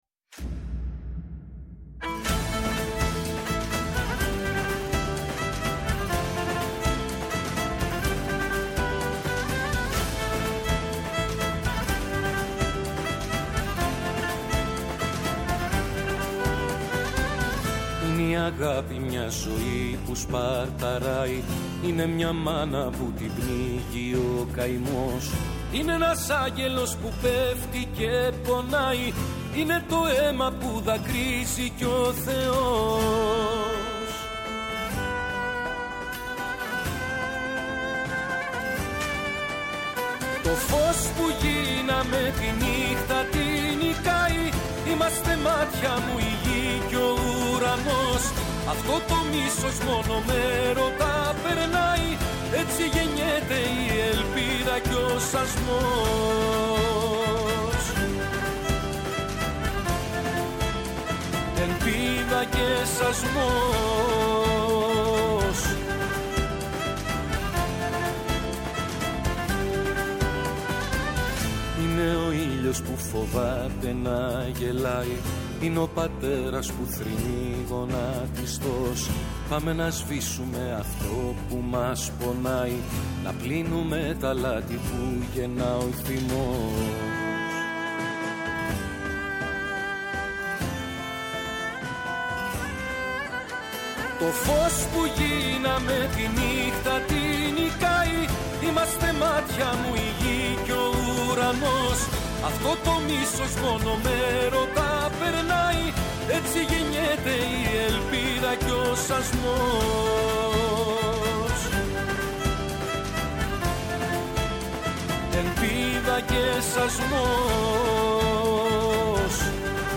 Καλεσμένος απόψε ο ερμηνευτής, Γιάννης Κότσιρας.